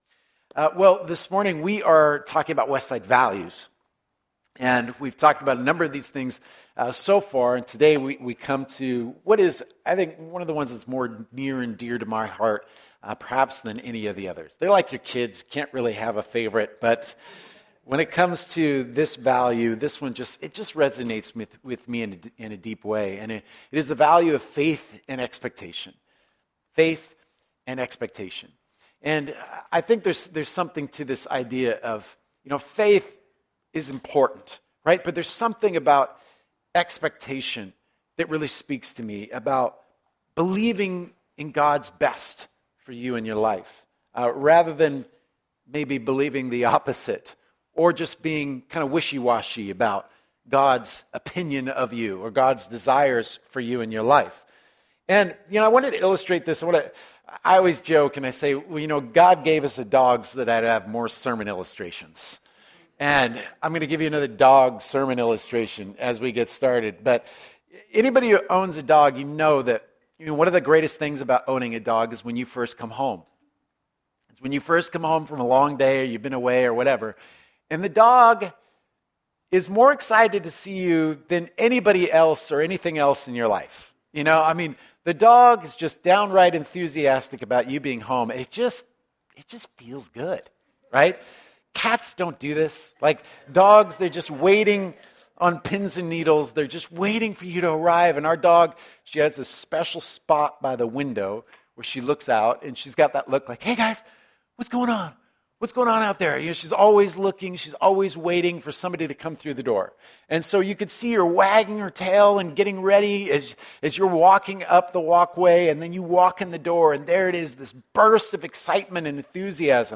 A few years ago, we set out to put words to those passions and we compiled a list of our core values. During this sermon series, we will be sharing them with you!